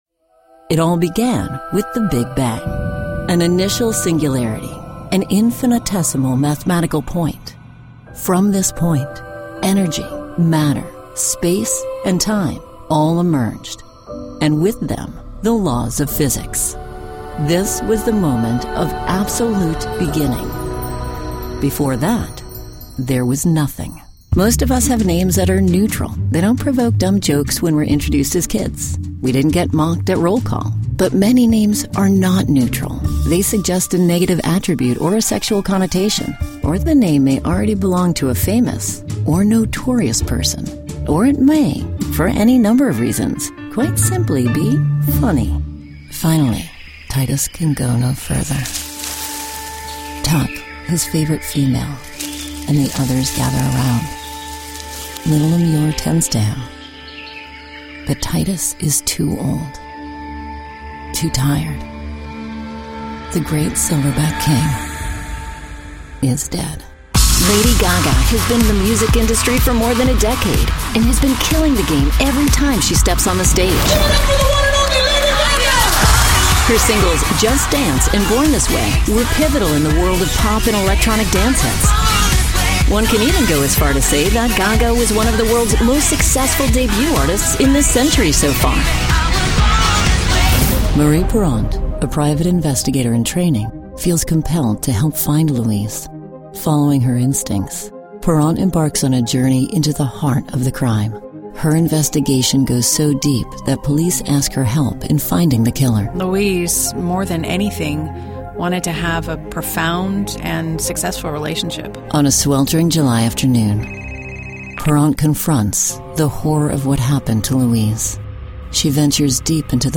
Narration
Vocalbooth which is housed in a sound treated room (for extra peach of mind :) )